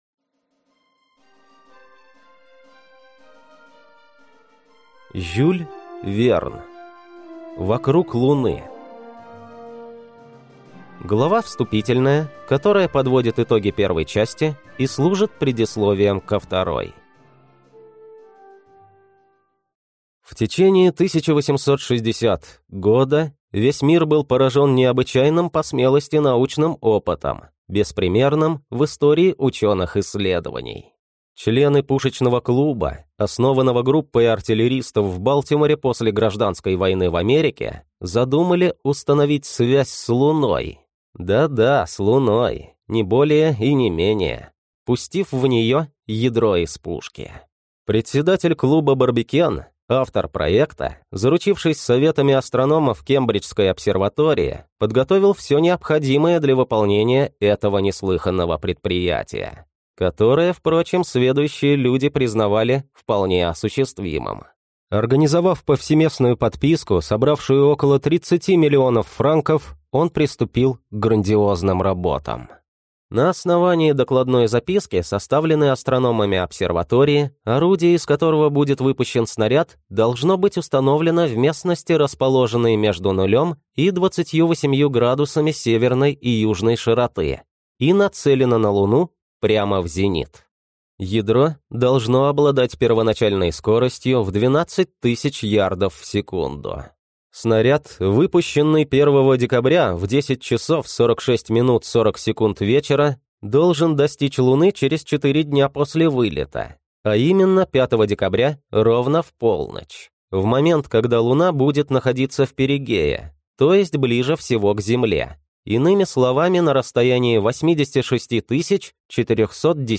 Аудиокнига Вокруг Луны | Библиотека аудиокниг